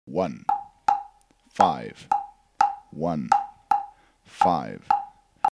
Par exemple, la partition suivante représente une clave dite "2-3", la plus fréquente dans les salsas actuelles.
clave23.mp3